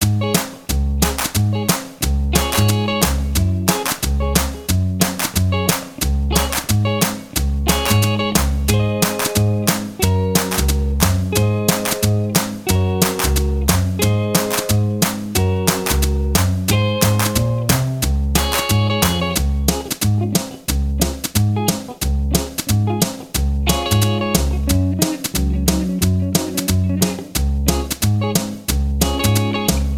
No Saxophone Pop (1960s) 2:45 Buy £1.50